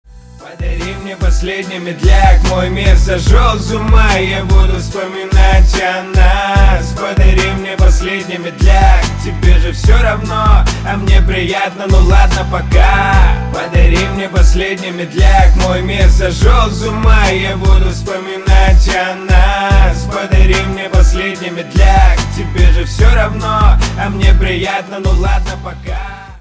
• Качество: 320, Stereo
рэп
Хип-хоп